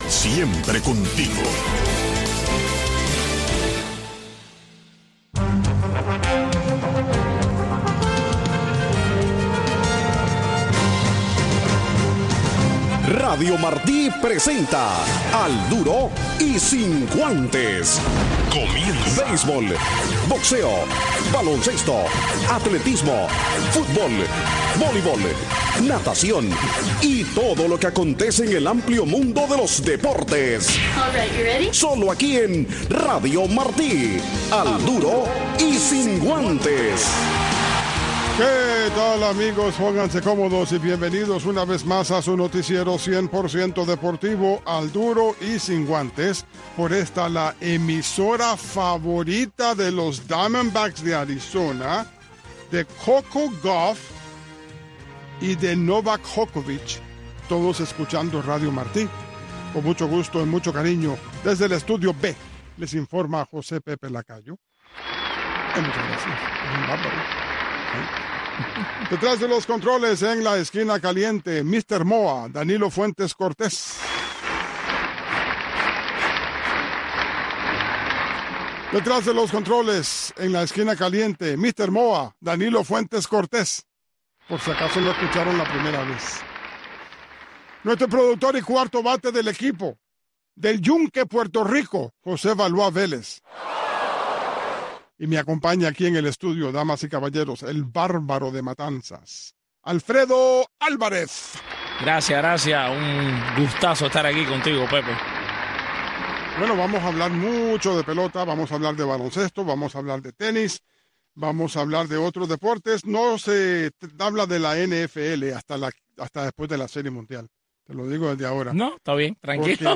Un resumen deportivo en 60 minutos conducido por